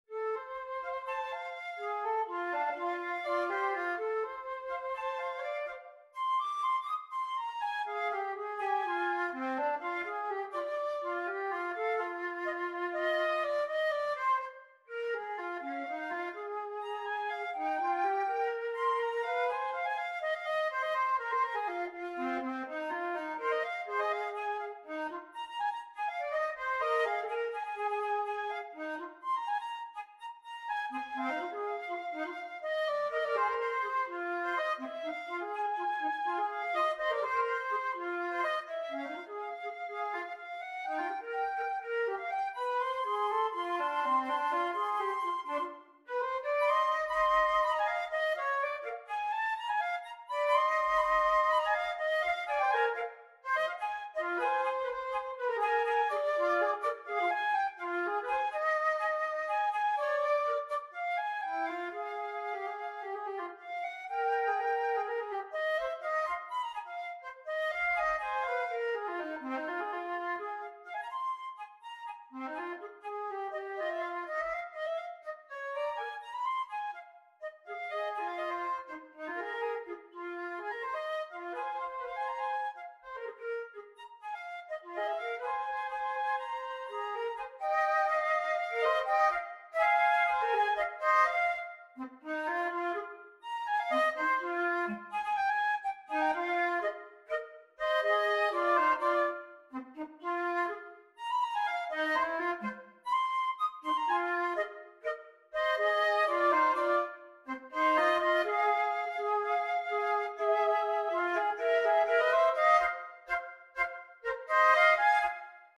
Gattung: Für 2 Flöten
Besetzung: Instrumentalnoten für Flöte